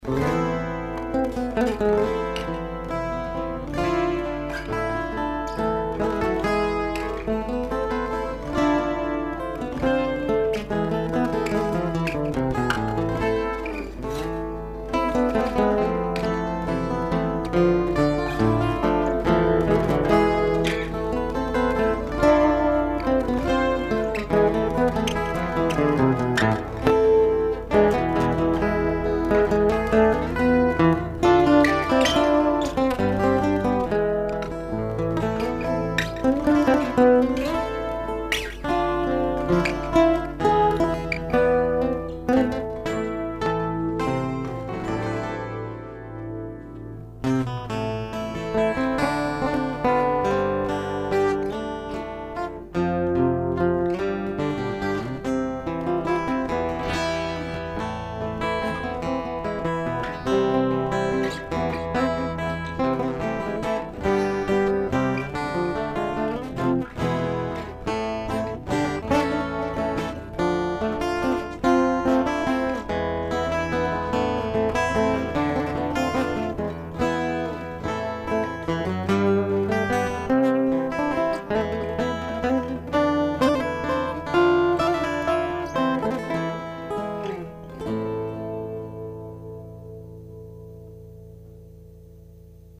инструментальная пьеса